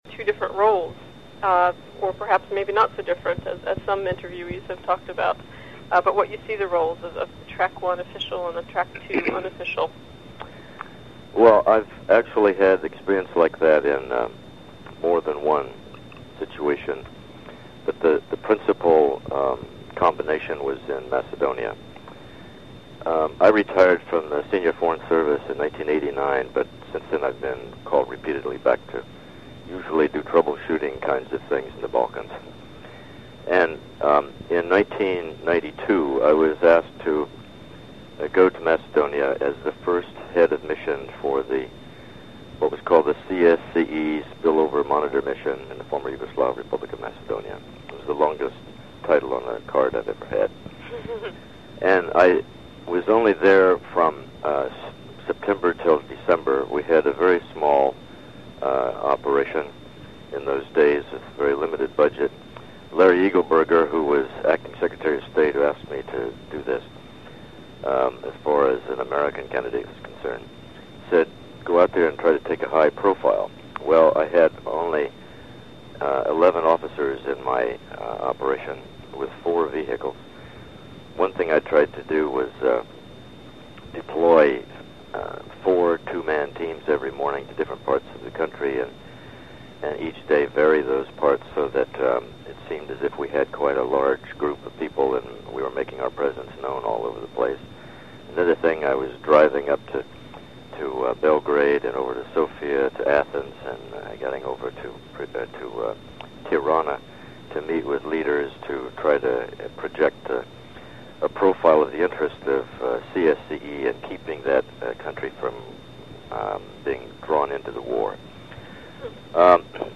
Listen to Full Interview This rough transcript provides a text alternative to audio.